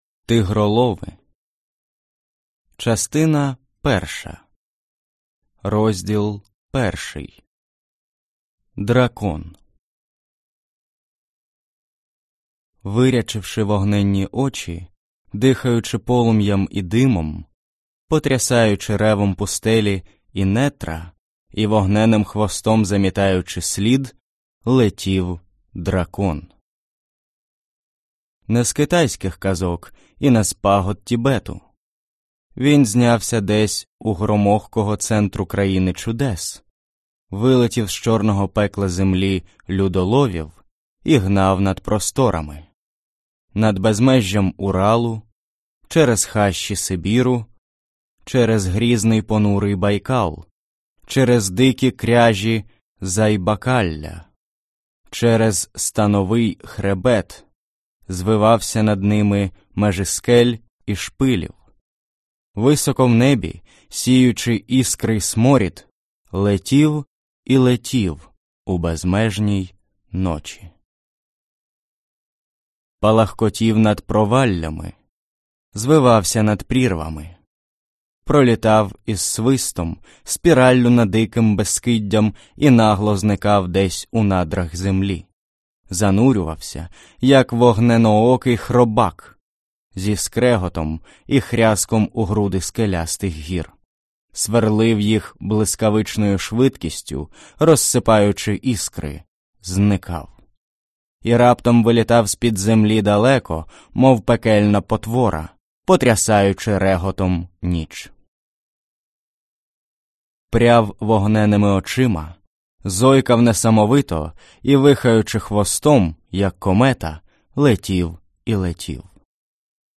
Аудиокнига Тигролови | Библиотека аудиокниг